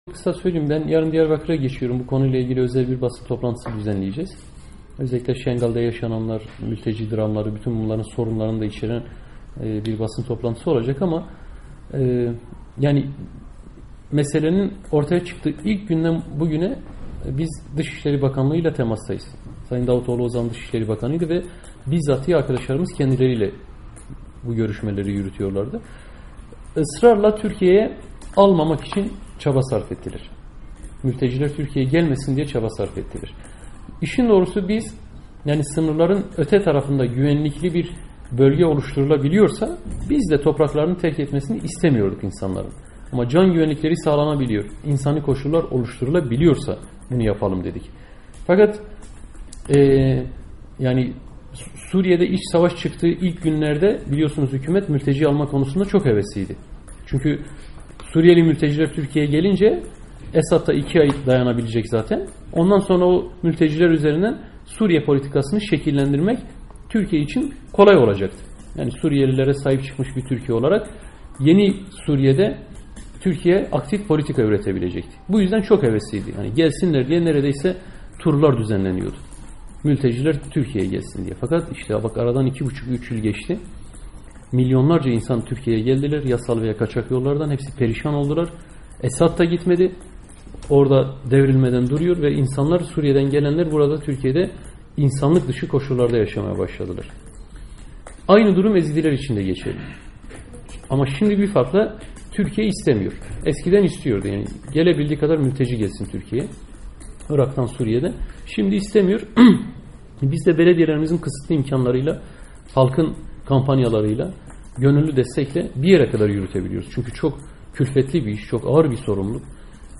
HDP Eş Genel Başkanı Selahattin Demirtaş, bir grup gazeteciyle sohbet toplantısında, Amerika'nın Sesi'nin sorusu üzerine Ezidiler'in durumunu değerlendirdi.
Selahattin Demirtaş'ın Açıklaması